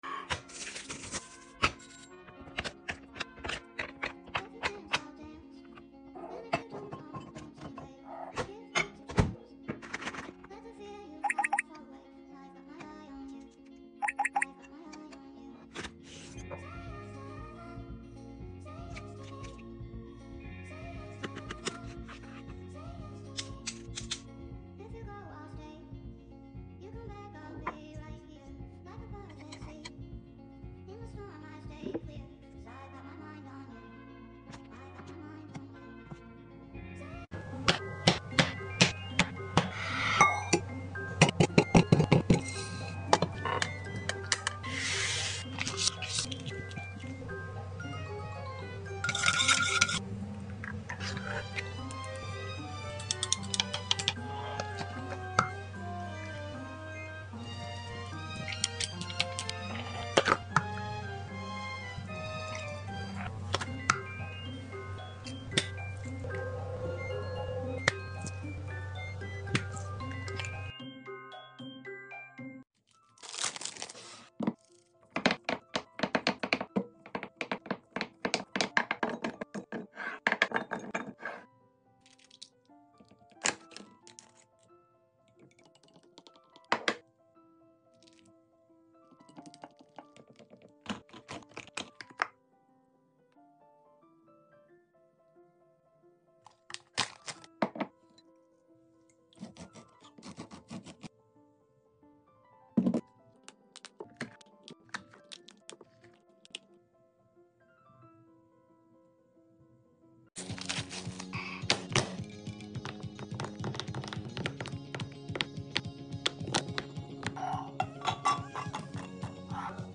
ASMR diy soap making 🧼🫧 sound effects free download